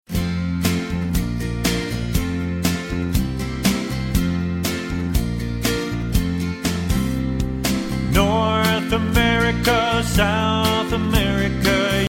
Vocal mp3 Track
MP3 Demo